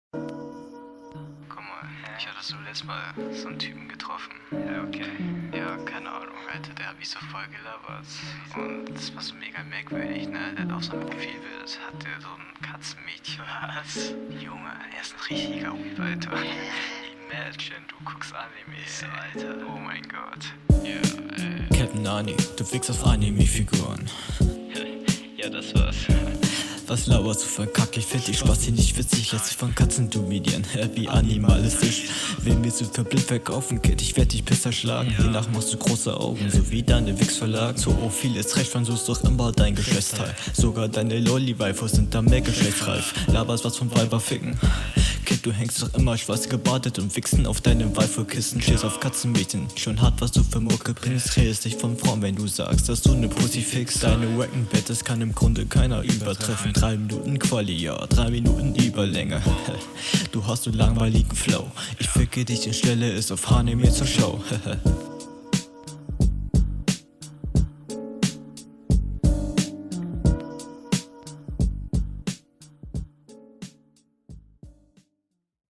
Flow: Hört sich sehr schön geflowt an.
Flow: ➨ Flow klingt grade für diese Liga schon sehr gut.